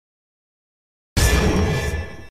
rogue-lineage-injure-sound.mp3